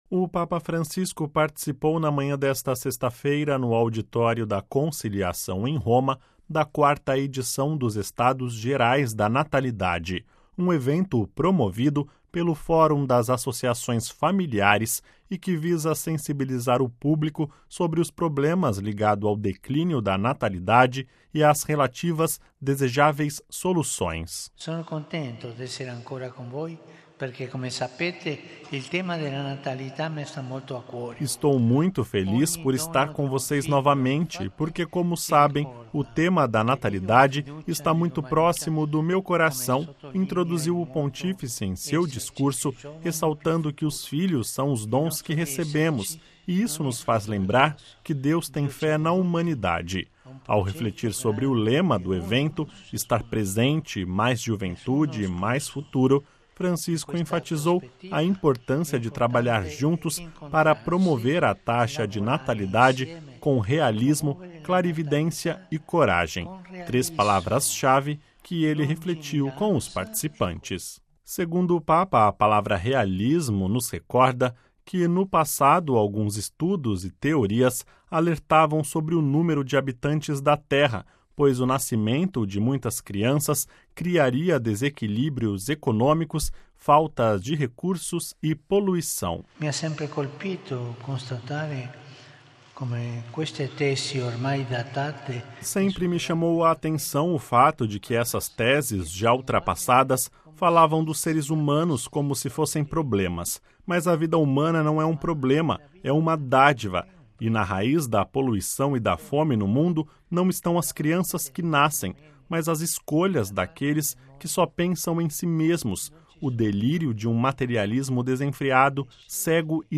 Ouça com a voz do Papa e compartilhe